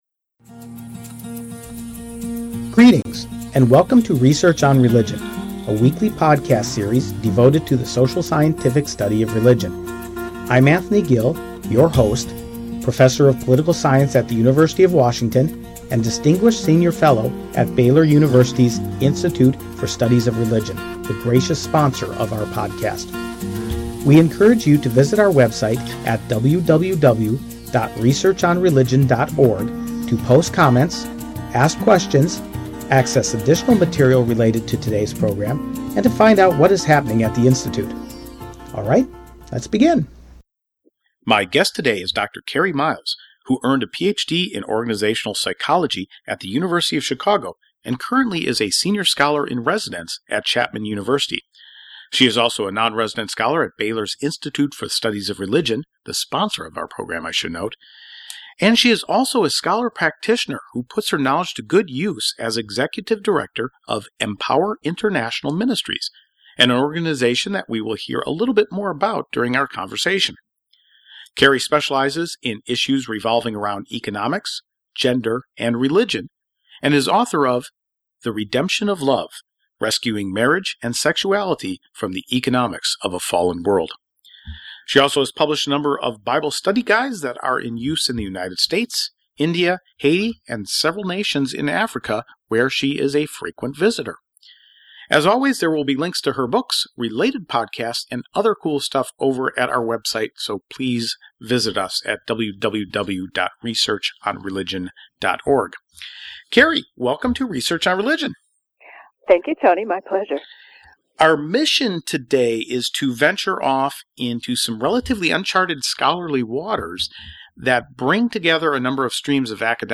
Our conversation winds through Mormon theology, Genesis, the industrial revolution, and countries in Africa to explore how both economic production and theology can shape gender roles throughout history.